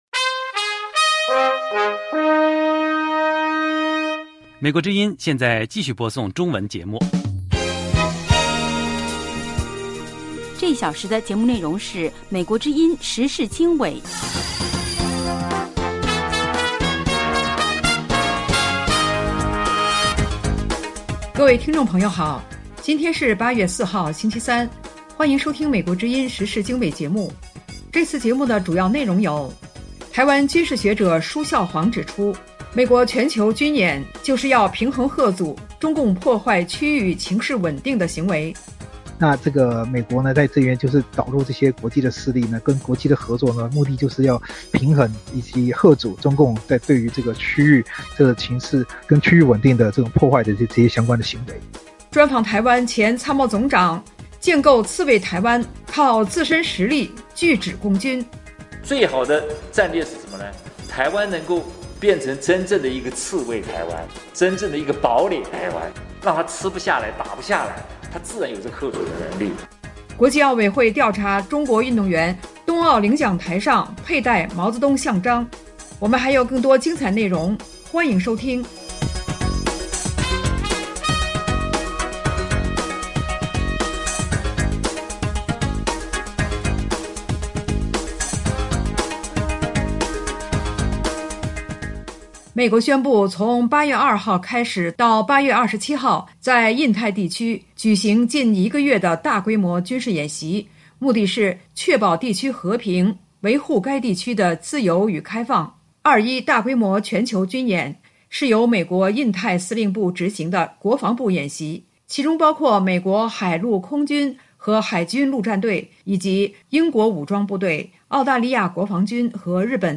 2/专访台湾前参谋总长：建构“刺猬台湾”靠自身实力拒止共军。3/国际奥委会调查中国运动员东奥领奖台上佩戴毛像章。